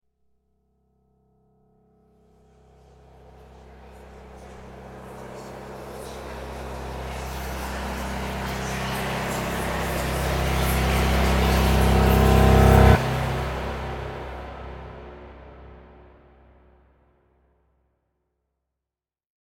Long Eerie Ghost Riser Sound Effect
Description: Long eerie ghost riser sound effect. Ideal for horror scenes, creepy trailers, haunted environments, and cinematic suspense.
Long-eerie-ghost-riser-sound-effect.mp3